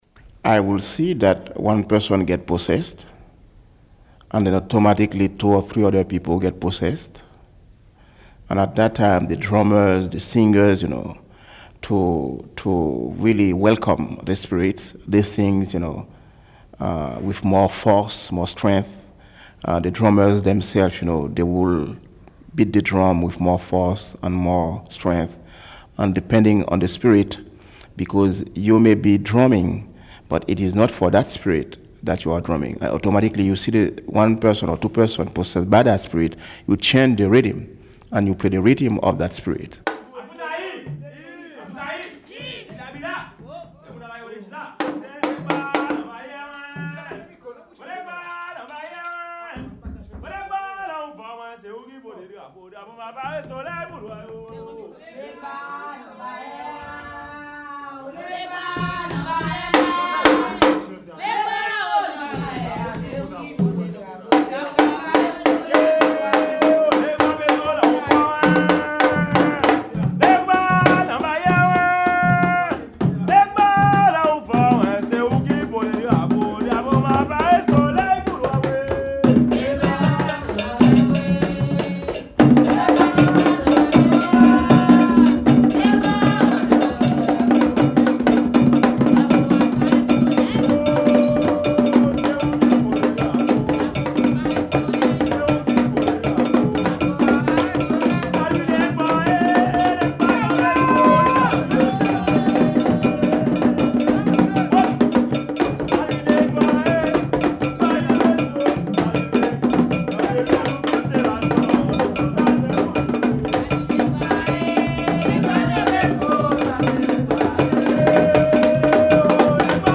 HAITIAN   Voudun religious ceremonies in New York